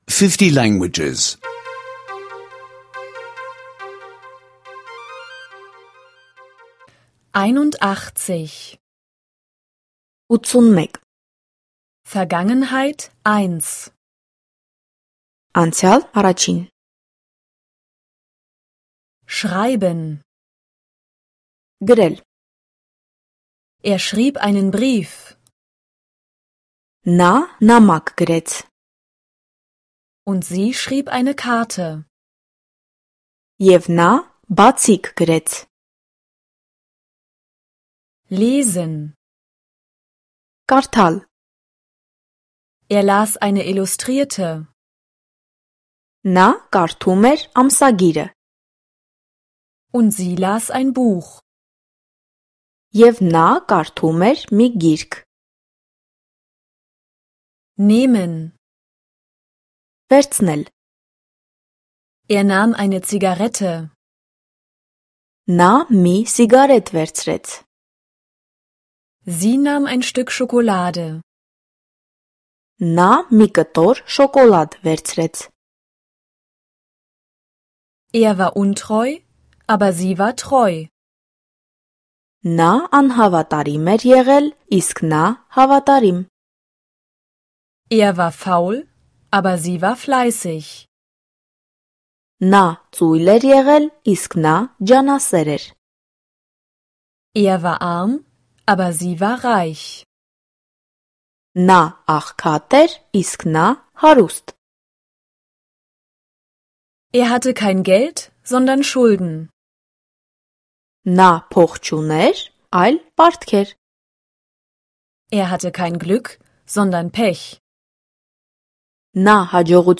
Armenische Audio-Lektionen, die Sie kostenlos online anhören können.